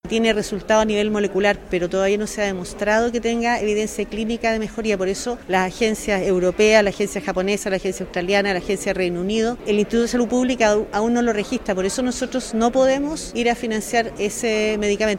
Al respecto, la ministra de Salud, Ximena Aguilera, explicó que si bien “tiene resultado a nivel molecular, todavía no se ha demostrado que tenga evidencia clínica de mejoría”.
ministra-ximena-aguilera.mp3